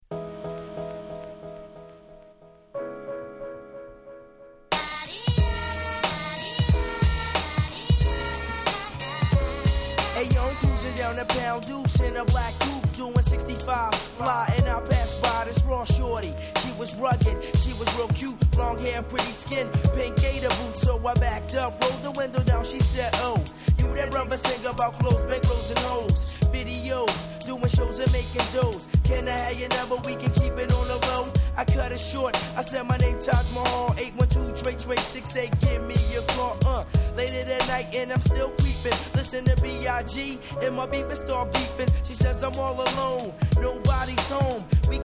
95' Hip Hop Classics.
90's Boom Bap ブーンバップ アングラ アンダーグラウンド Underground